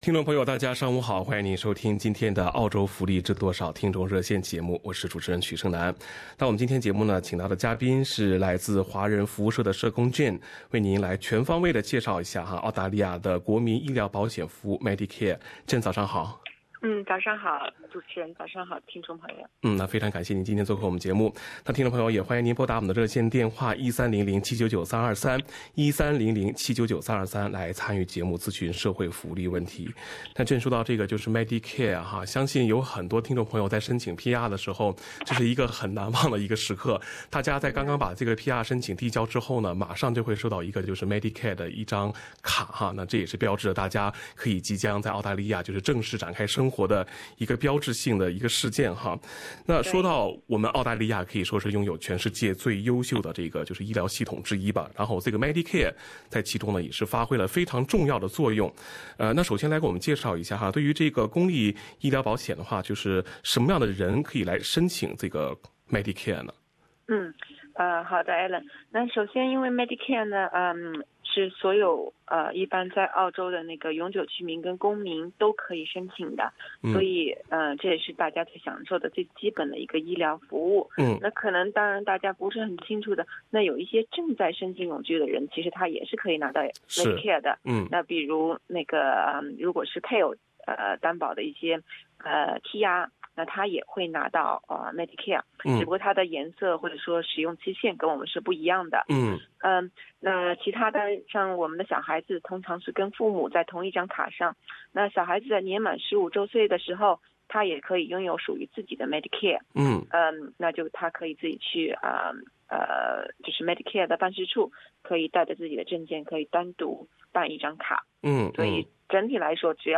《澳洲福利知多少-听众热线》逢每月第四个周一上午8点至9点播出。